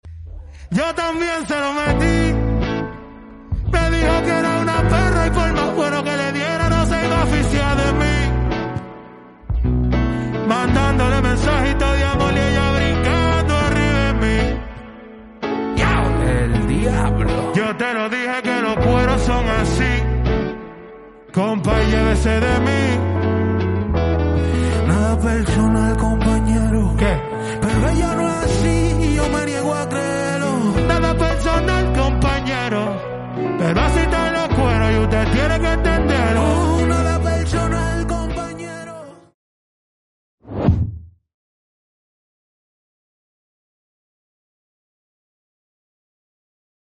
NADA PERSONAL COMPAÑERO EN VIVO